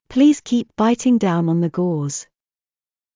ﾌﾟﾘｰｽﾞ ｷｰﾌﾟ ﾊﾞｲﾃｨﾝｸﾞ ﾀﾞｳﾝ ｵﾝ ｻﾞ ｺﾞｰｽﾞ